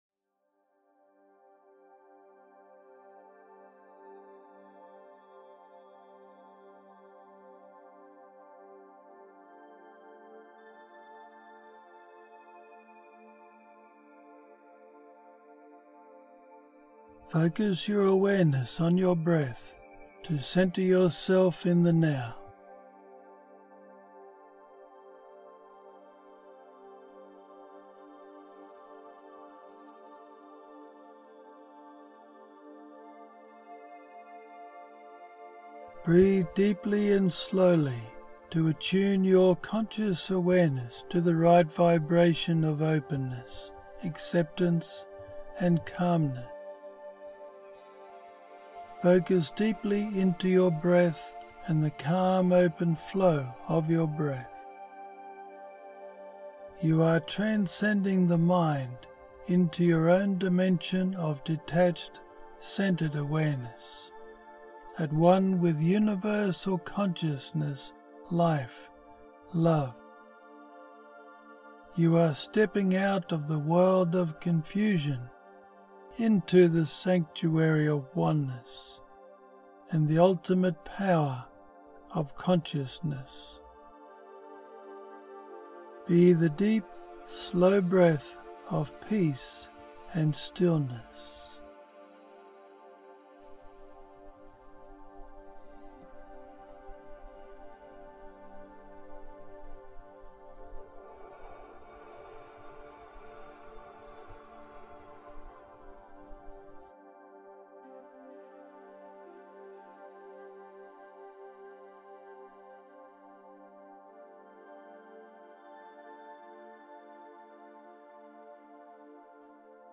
This guided meditation is a more advanced level meditation, that focuses on deepening and strengthening your ability to be the detached and compassionate observer of your human-self.
I have left time at the end of this meditation with the background music still running for you to do your own practice.